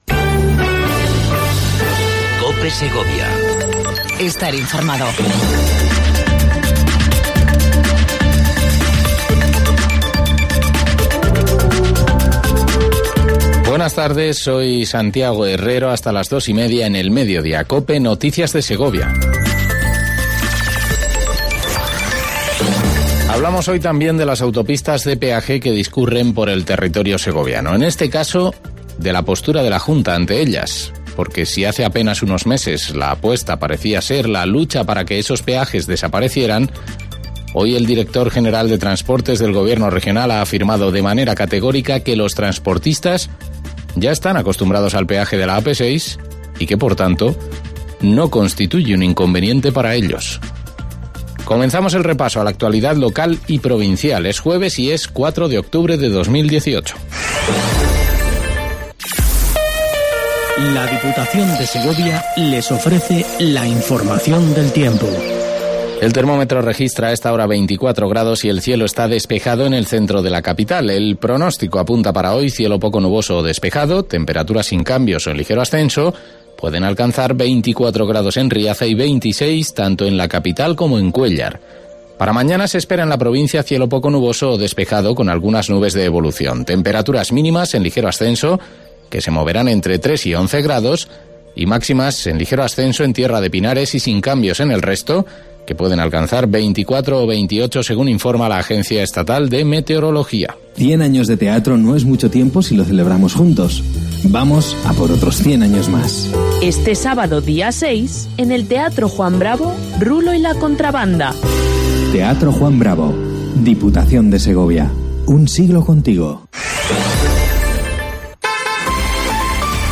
AUDIO: Repaso informativo a la actualidad local y provincial 04/10/18